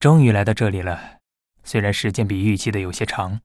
文件 文件历史 文件用途 全域文件用途 Bhan_amb_04.ogg （Ogg Vorbis声音文件，长度3.4秒，102 kbps，文件大小：43 KB） 源地址:地下城与勇士游戏语音 文件历史 点击某个日期/时间查看对应时刻的文件。